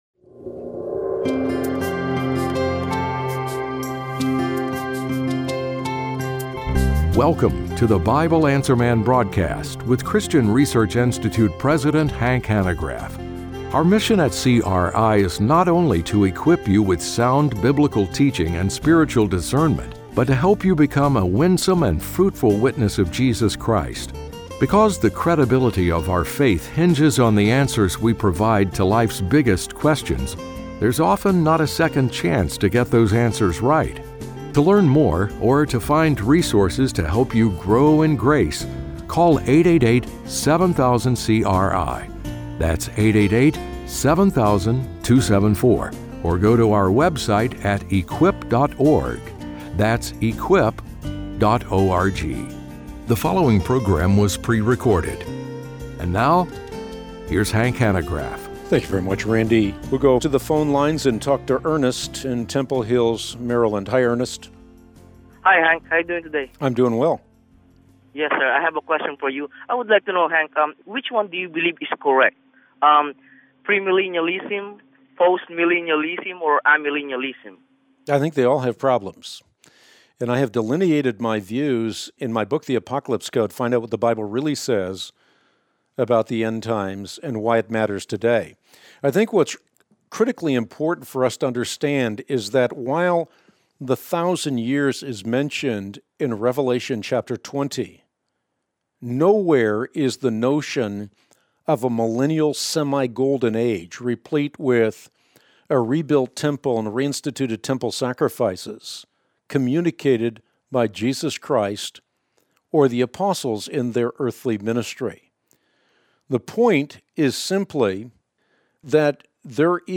Q&A: The Millennium, What Eternity Will Be Like, and the Antichrist - Christian Research Institute